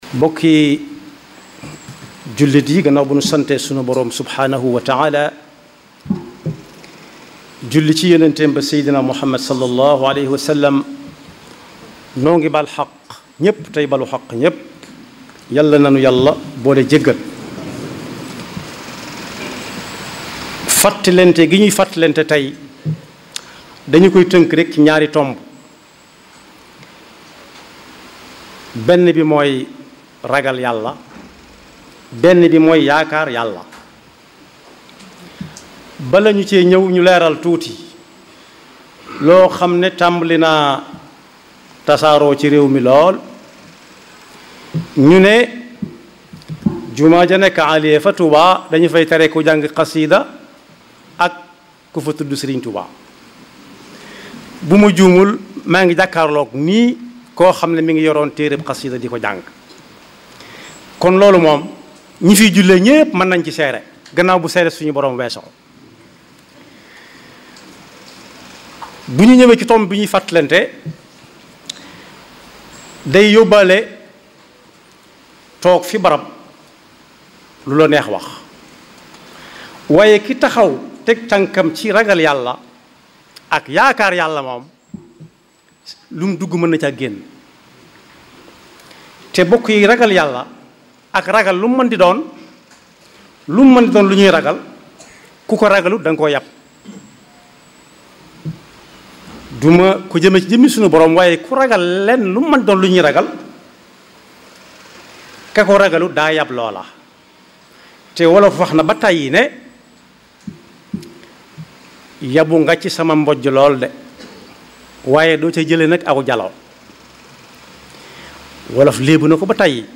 Écouter et Télécharger Les Khoutba